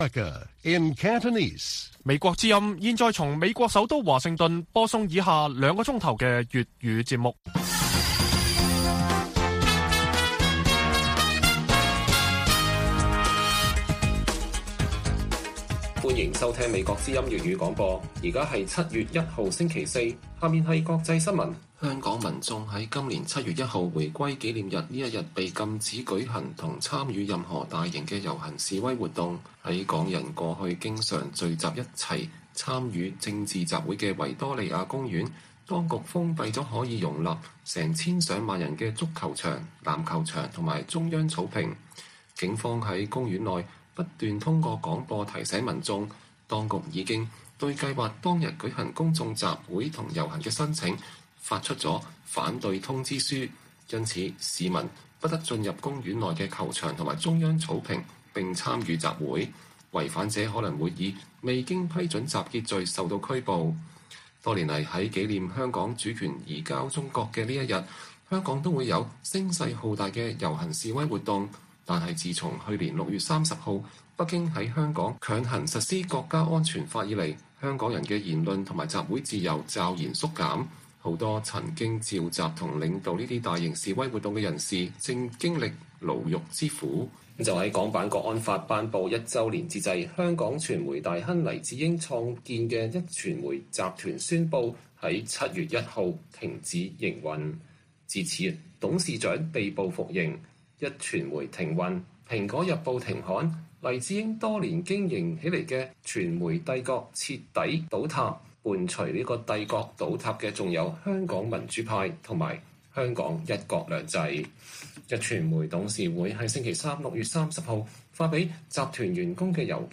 粵語新聞 晚上9-10點: 香港人在高壓下渡過“回歸”紀念日